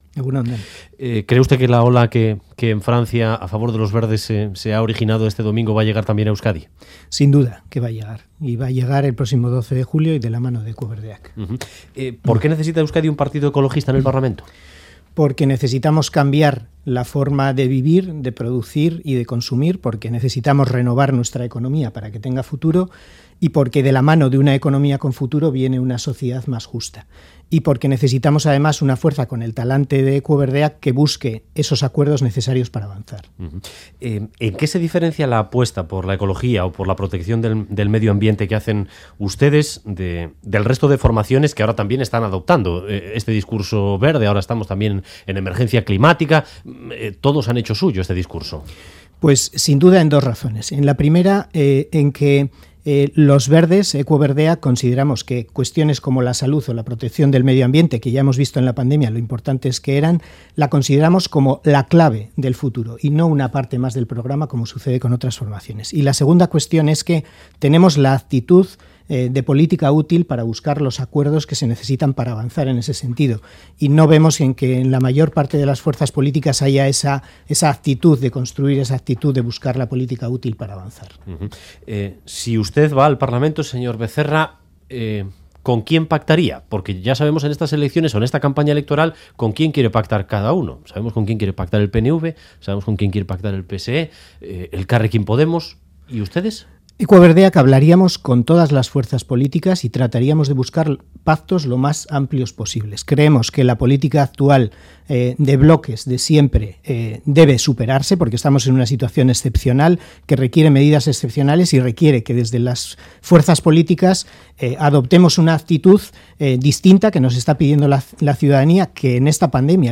ofrece en Radio Euskadi una entrevista elecciones autonómicas vascas del 12 de julio.